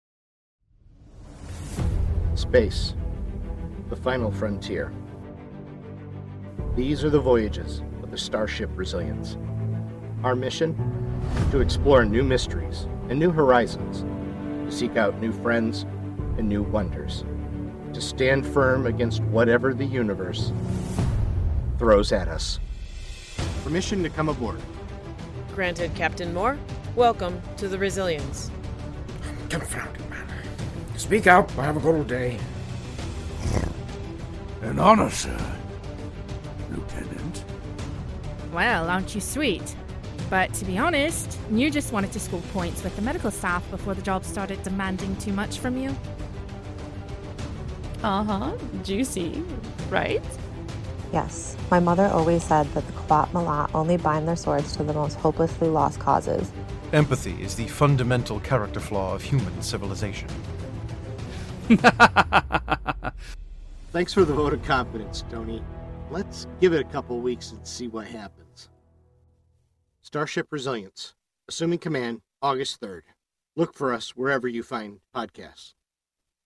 Format: Audio Drama
Voices: Full cast
Narrator: First Person
Soundscape: Sound effects & music